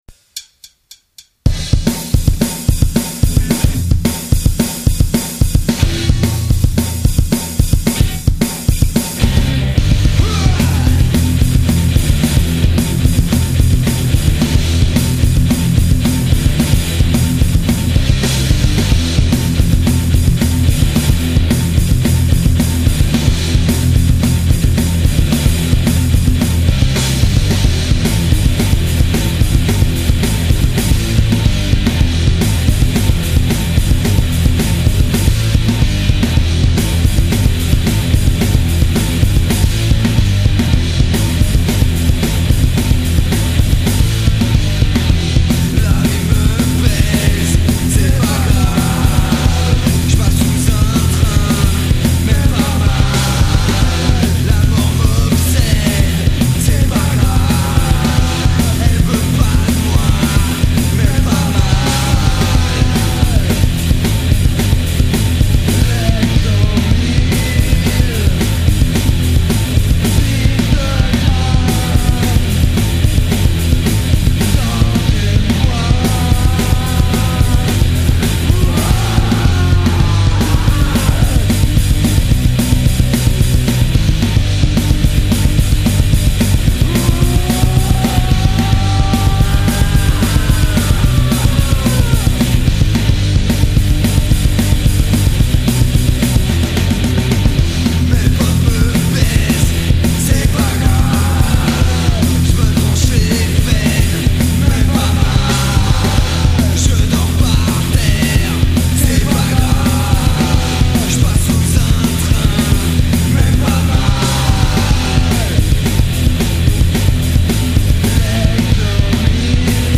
guitare voix boite à ryhtme